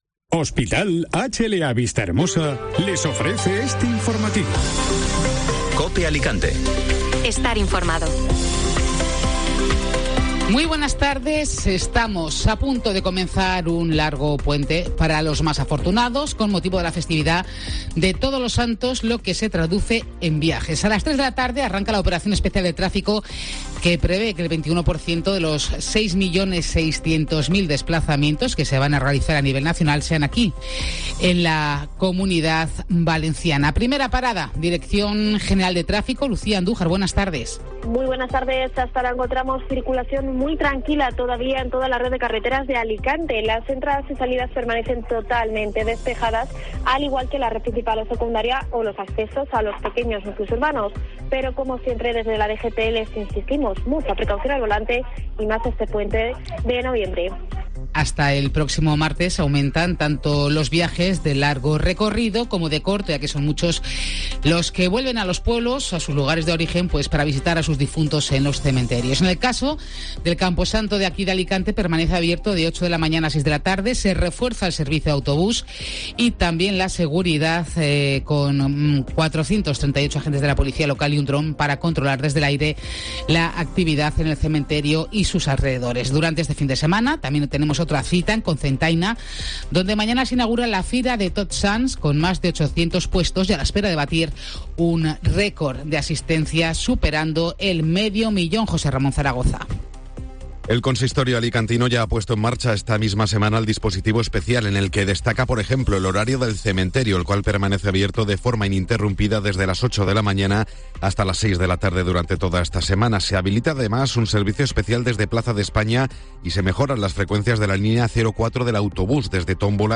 Informativo Mediodía COPE (Viernes 28 de octubre)